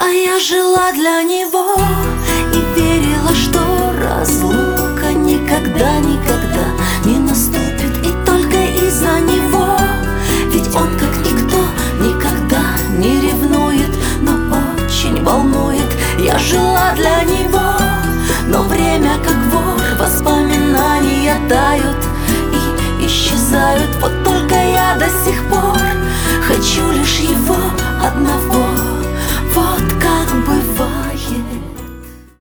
• Качество: 320, Stereo
женский вокал
лирика
спокойные